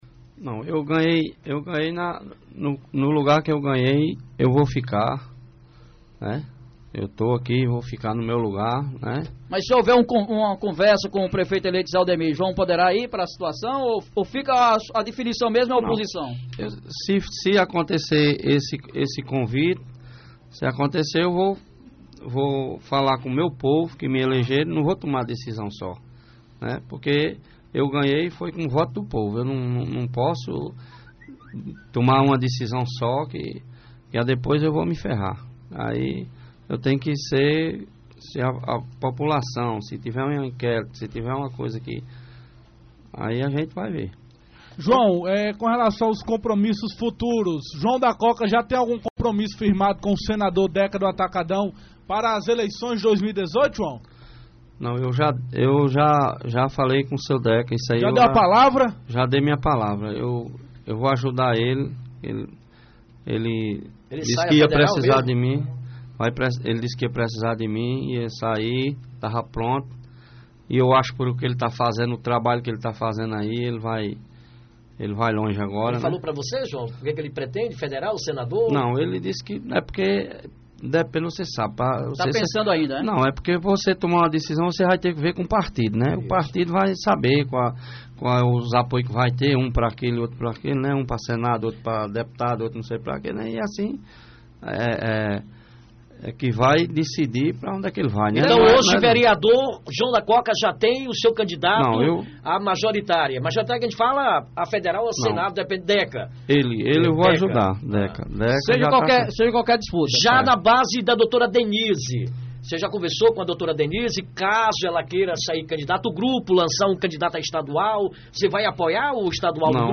Em entrevista ao Programa Rádio Vivo pela Rádio Alto Piranhas-AM o vereador recém eleito João Lins, mais conhecido popularmente João da Coca fez o seu agradecimento ao eleitores que lhe confiaram o mandato de vereador a partir de Janeiro de 2017, mas também respondeu perguntas da produção do programa quanto aos seus futuros planos, tanto na Câmara Municipal como compromissos políticos visando 2018.